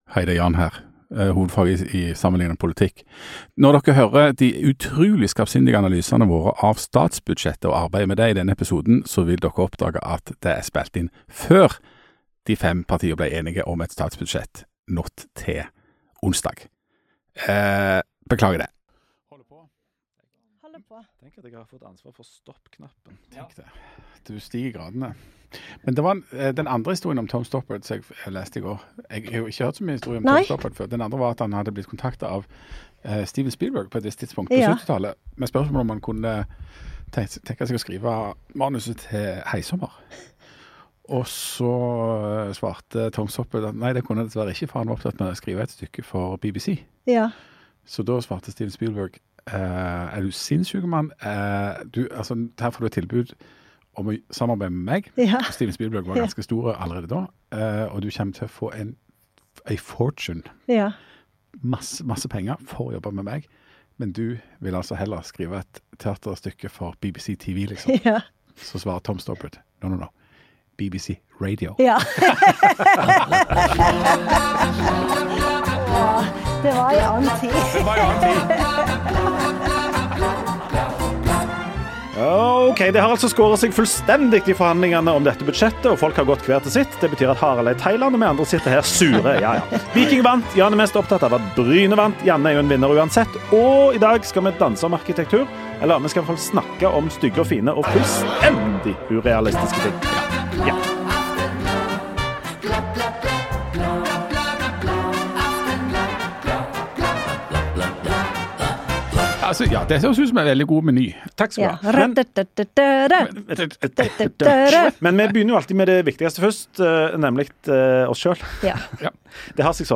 Ukesaktuelt nyhetsmagasin med lause snipp.